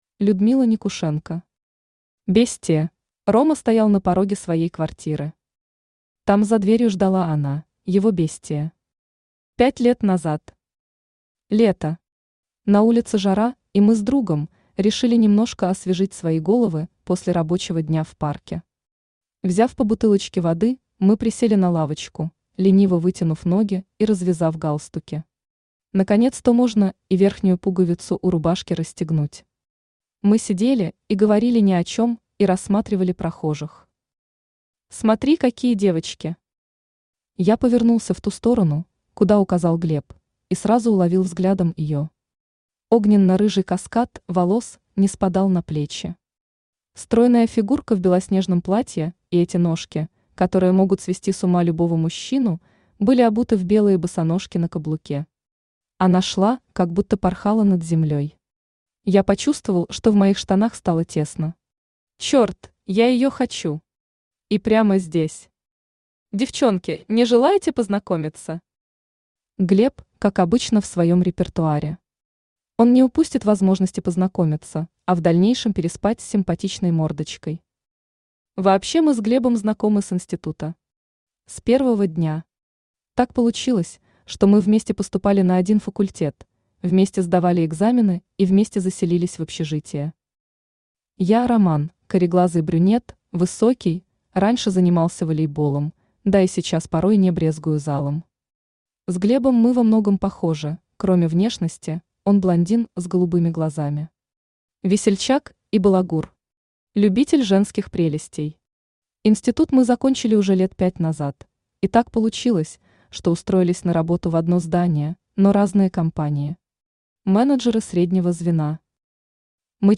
Аудиокнига Бестия | Библиотека аудиокниг
Aудиокнига Бестия Автор Людмила Никушенко Читает аудиокнигу Авточтец ЛитРес.